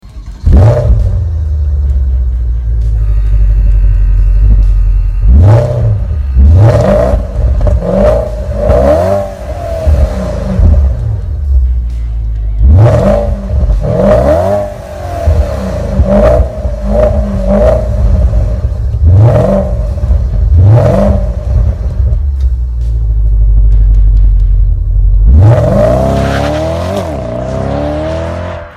ENGINE SIZE 4.8 L V8
Porsche-Panamera-engine-sound.mp3